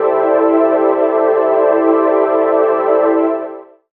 36ah01pad-eM.wav